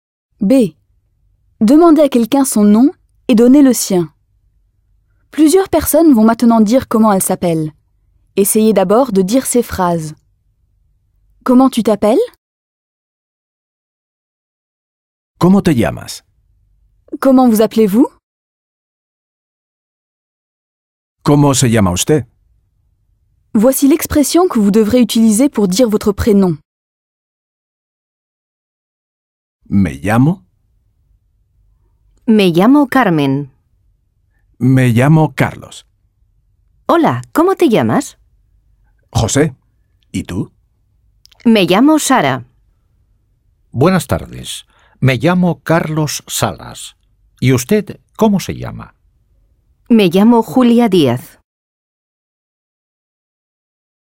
Icône de l'outil pédagogique ECOUTE DIALOGUE